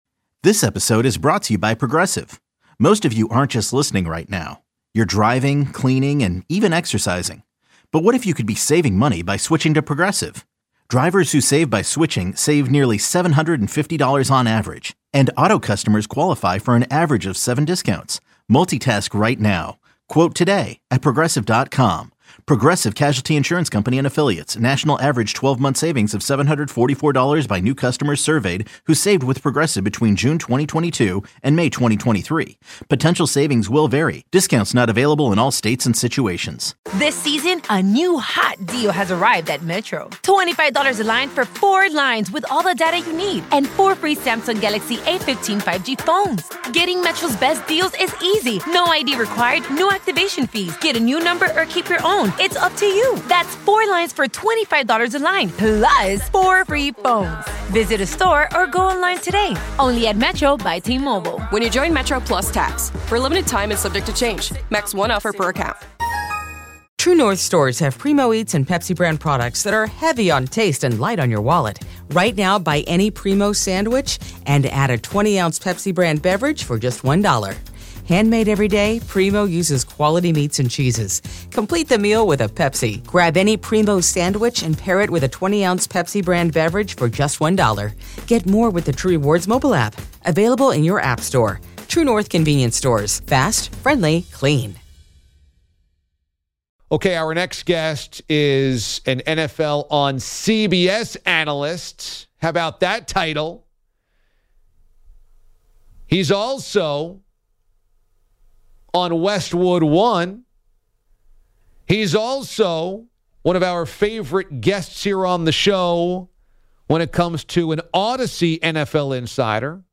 Audacy NFL Insider Ross Tucker swings by and gets immediately pressed by DA about his Taysom Hill - Jim Thorpe comparison. Also, they discuss a big Week 3 in the NFL coming up, along with the Ole Miss - Alabama game that Ross is calling this weekend.